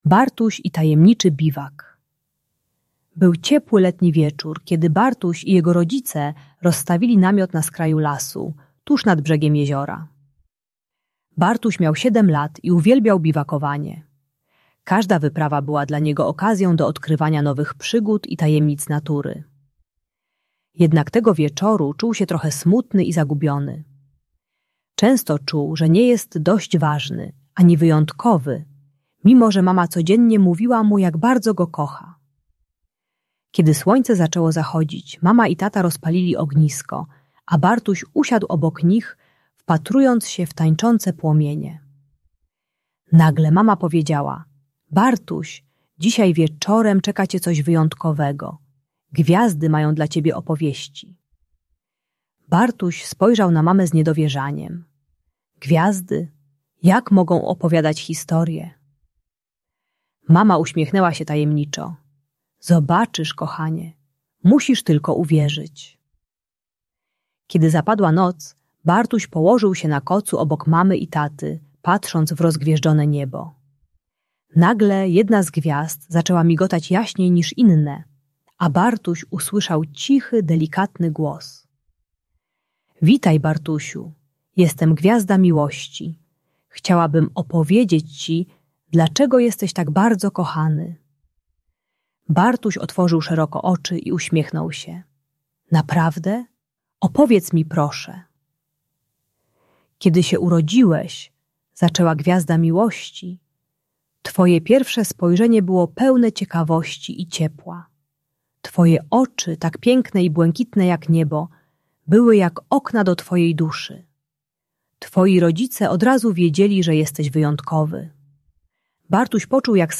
Opowieść o Bartusiu - Przywiązanie do matki | Audiobajka